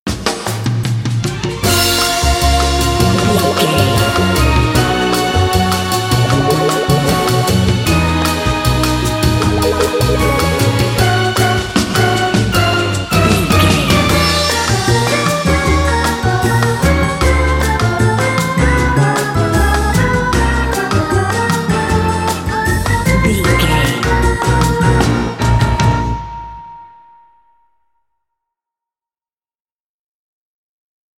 Uplifting
Aeolian/Minor
percussion
flutes
piano
orchestra
double bass
circus
goofy
comical
cheerful
perky
Light hearted
quirky